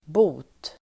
Uttal: [bo:t]